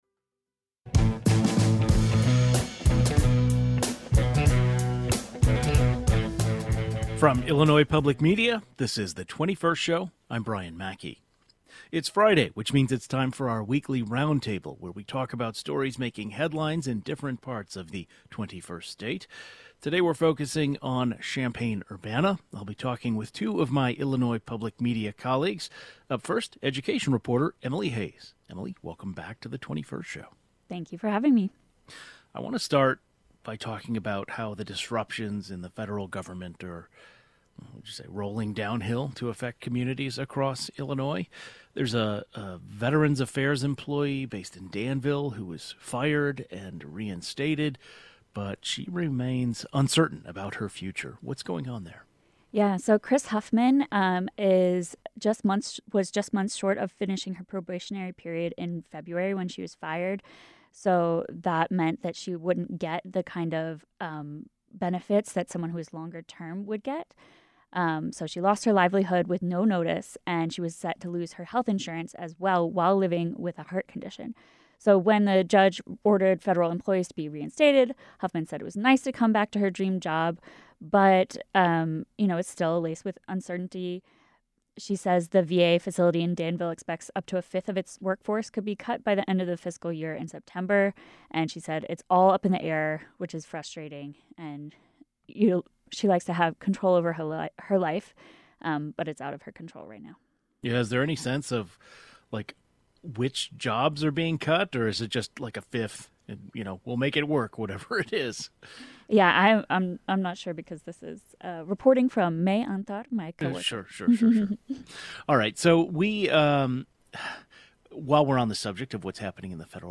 In our Friday Reporter Roundtable, we turn to news in Champaign-Urbana: